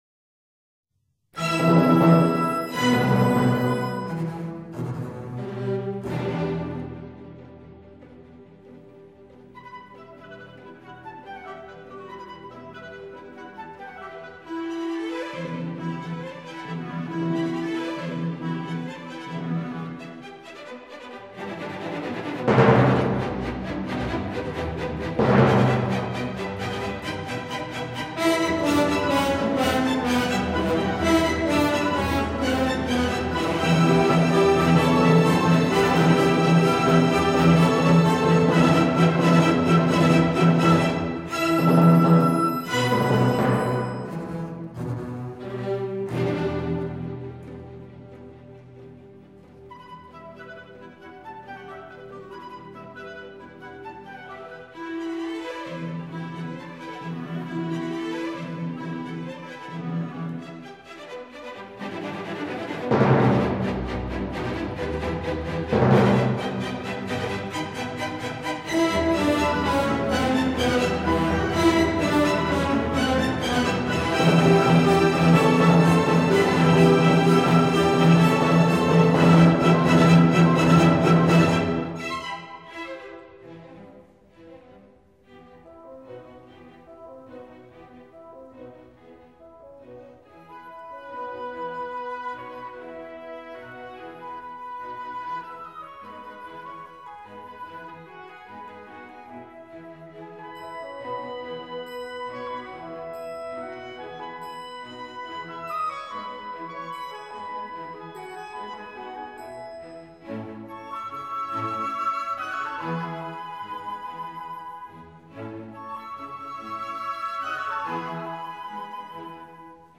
主部主题在全乐队有力的和弦伴奏下，由圆号和小号奏出威武雄壮的进行曲风格的旋律。
副部主题由单簧管在宁静的弦乐背景上，奏出富有歌唱性的柔美、抒情的旋律。
结束部，威武雄壮的进行曲再次出现，交响曲在极其辉煌的乐队全奏中结束。